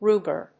/ˈrugɝ(米国英語), ˈru:gɜ:(英国英語)/